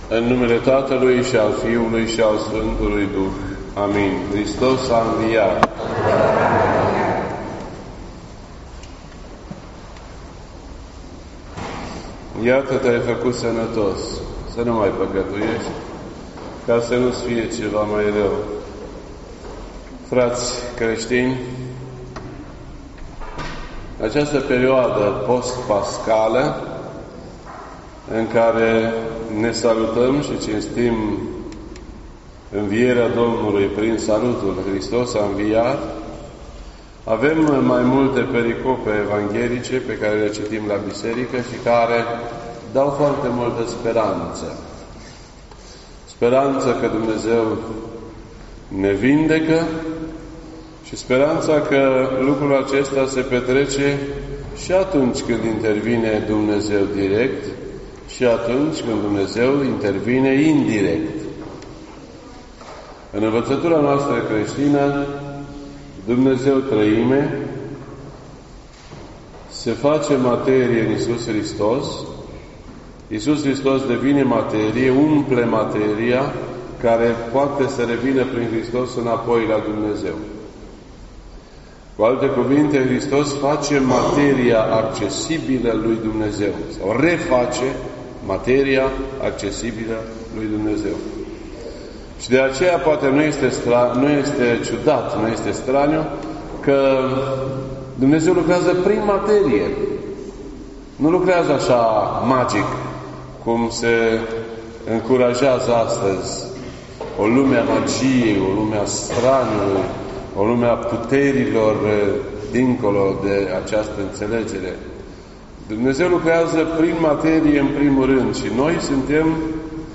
This entry was posted on Sunday, May 19th, 2019 at 7:34 PM and is filed under Predici ortodoxe in format audio.